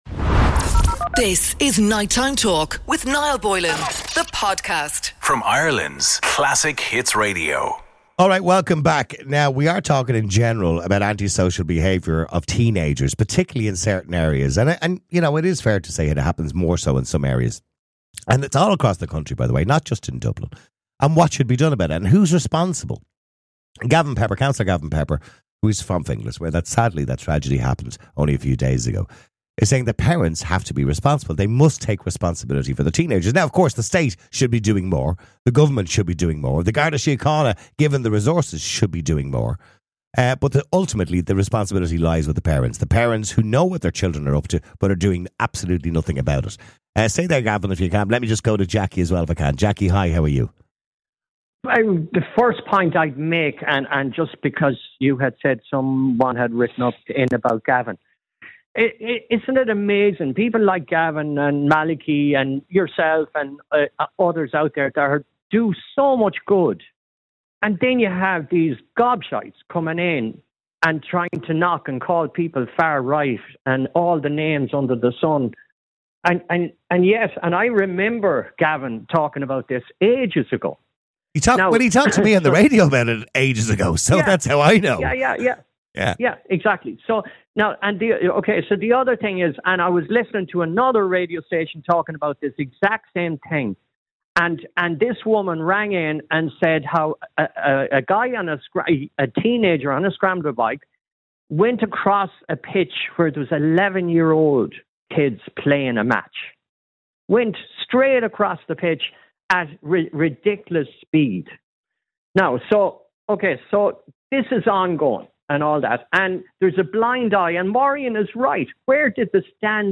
A radio talk show that cares about YOU